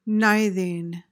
PRONUNCIATION:
(NY-thing, second syllable as in clothing)